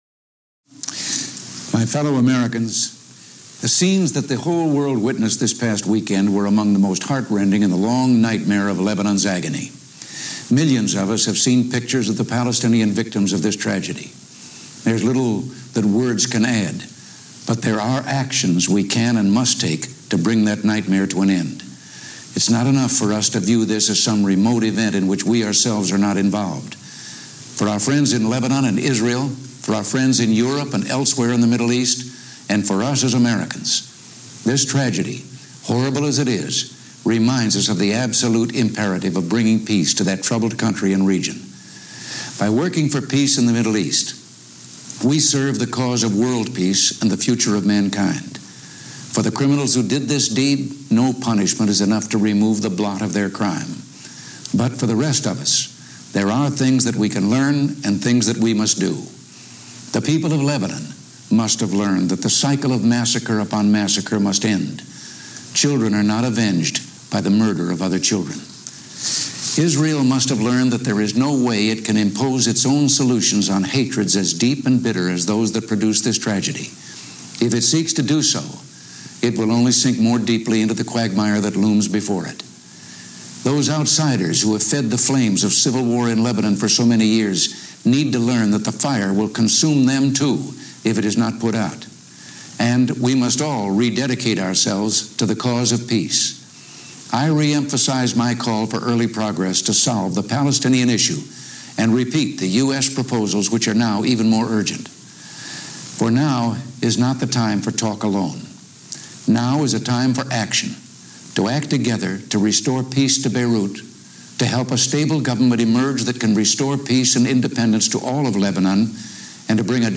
Address to the Nation on the Deployment of a Multinational Peace-Keeping Force in Lebanon
delivered 20 September 1982, Oval Office, White House, Washington, D.C.
Audio Note: AR-XE = American Rhetoric Extreme Enhancement